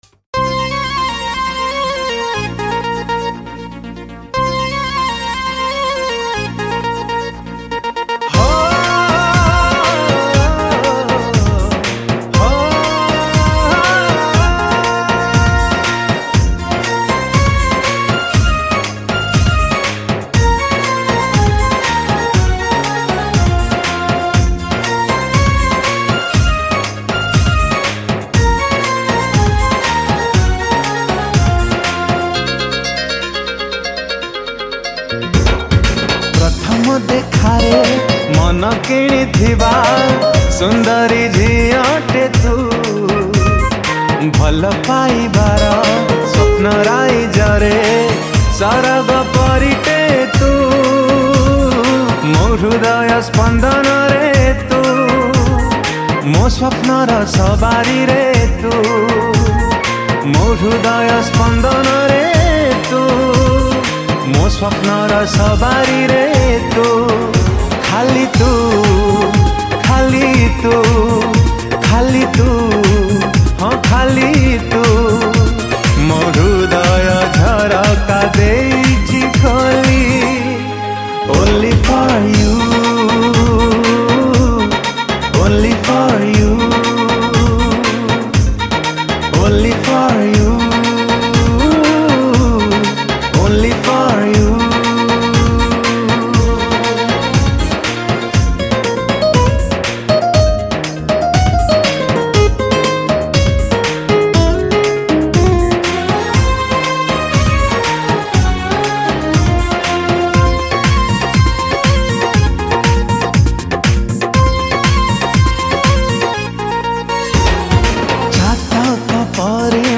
Recorded At : MRR Studio,Cuttack